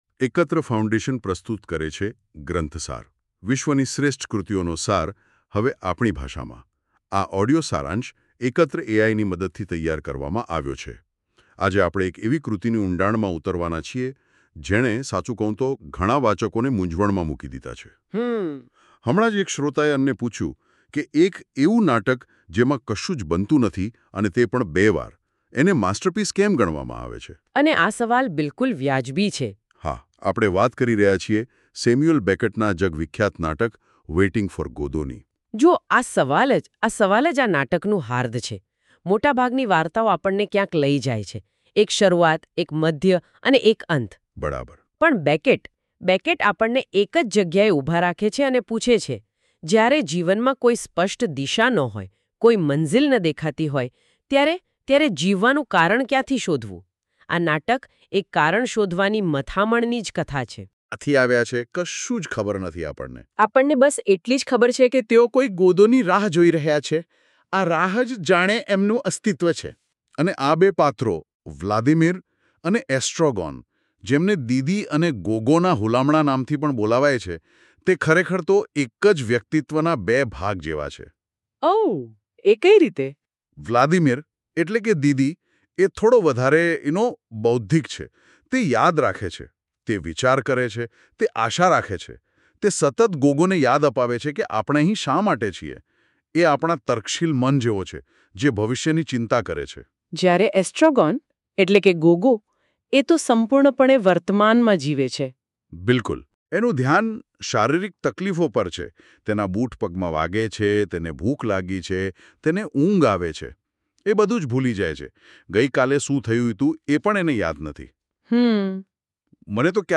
Ekatra audio summary – Gujarati